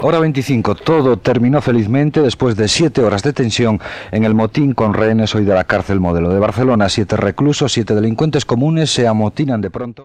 Explicació dels dos periodistes de Ràdio Barcelona que van poder entrar a la presó per informar del motí.
Informatiu